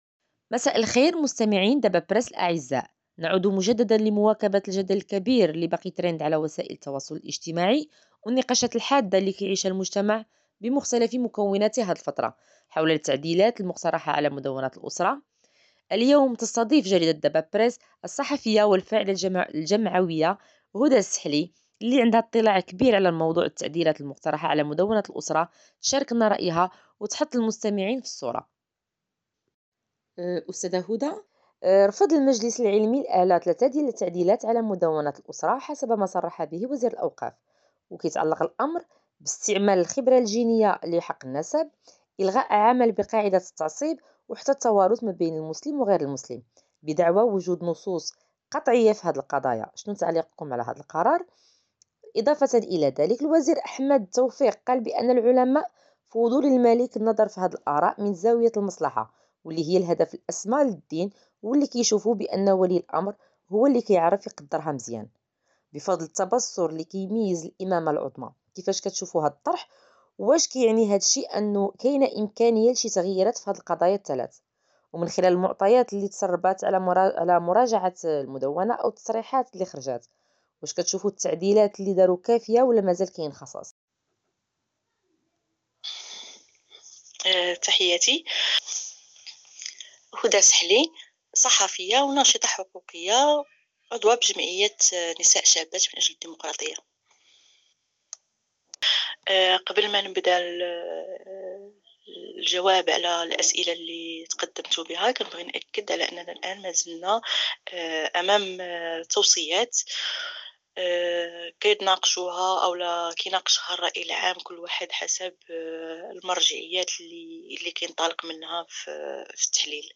أجرت الجريدة حواراً خاصاً